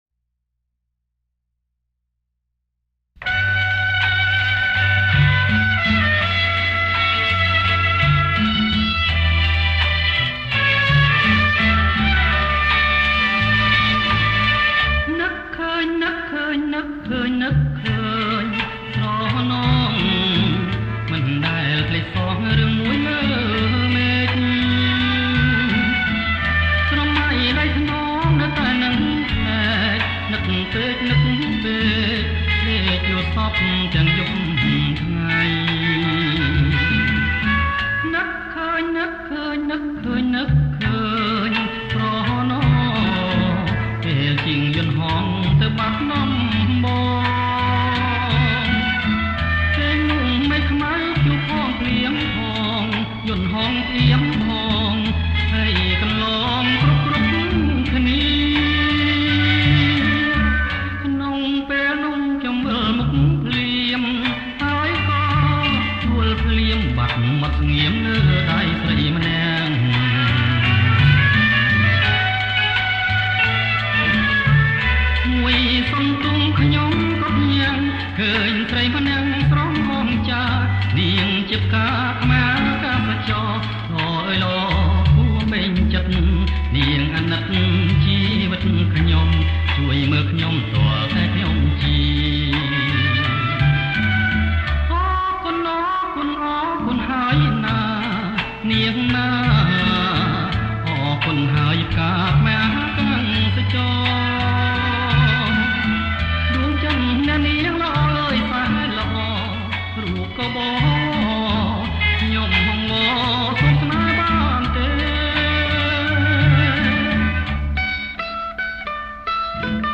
ប្រគំជាចង្វាក់ Bolero Lent